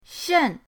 shen4.mp3